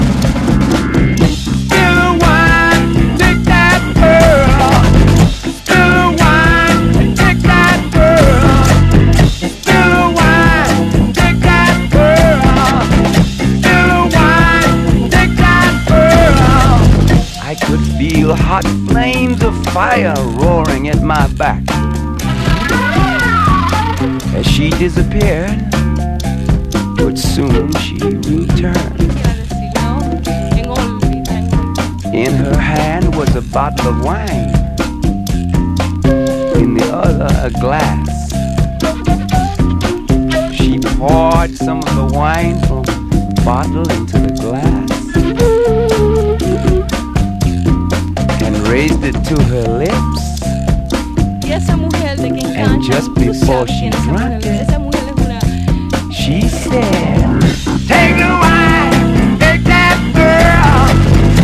ROCK / 60'S / PSYCHEDELIC
メロウなサイケデリック・ポップ
荒々しいガレージ・サイケな
シタール＆ホーンが熱く絡み合うファンキー・サイケデリック・グルーヴ！